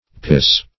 pisay - definition of pisay - synonyms, pronunciation, spelling from Free Dictionary Search Result for " pisay" : The Collaborative International Dictionary of English v.0.48: Pisay \Pi"say\, n. (Arch.)